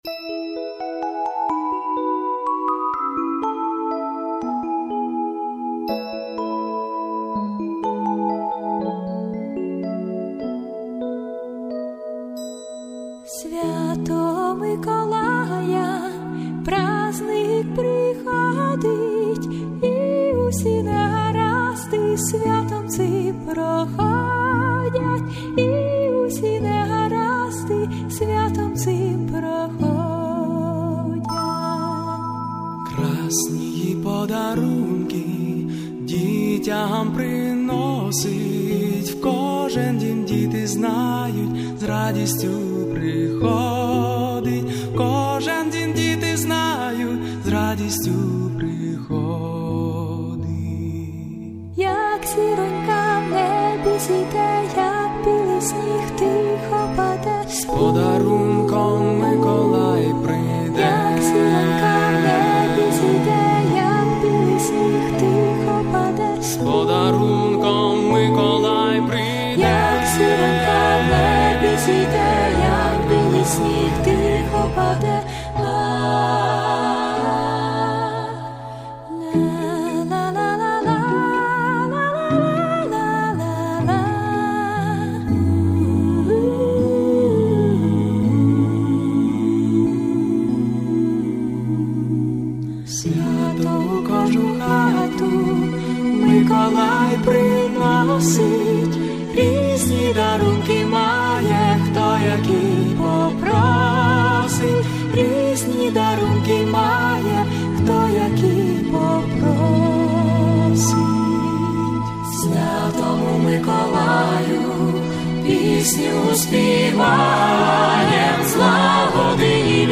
Спасибо за нежную песенку!